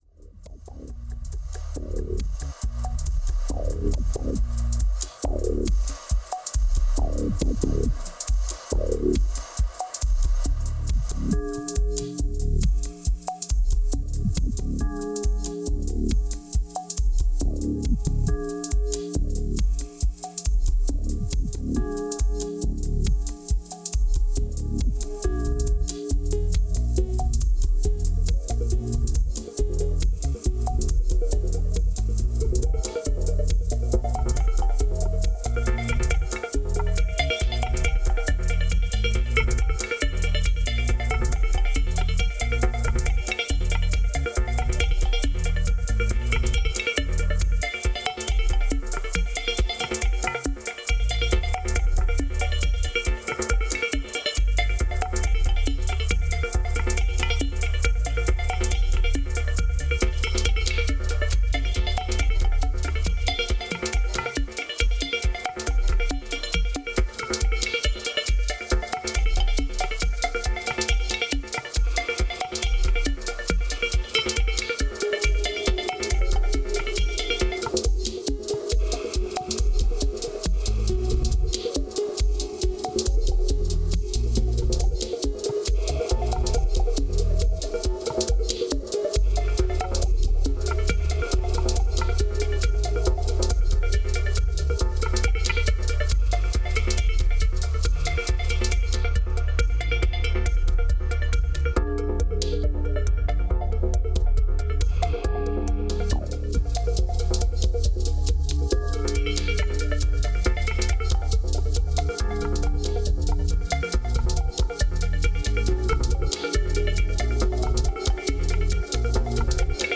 2408📈 - 49%🤔 - 69BPM🔊 - 2017-06-06📅 - 352🌟